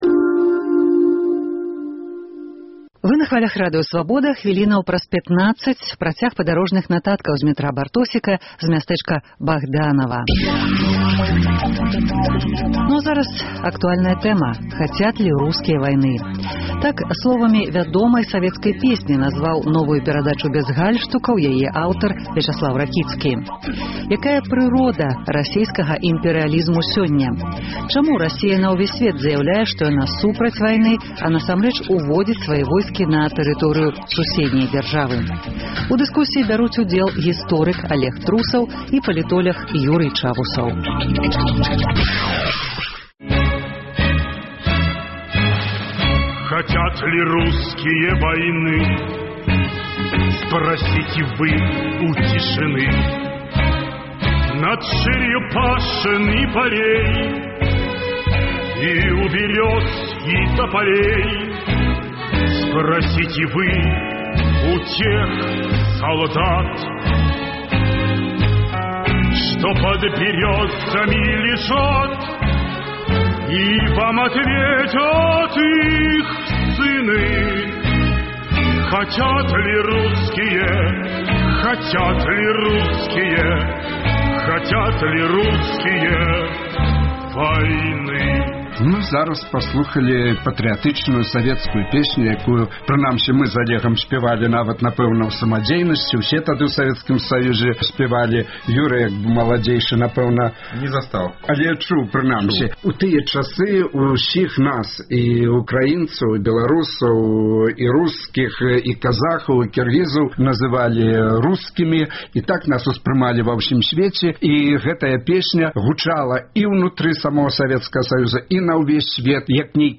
Чаму замест дыпляматыі расейцы ўжываюць сілу ў разьвязаньні праблемаў зь незалежнымі дзяржавамі?У дыскусіі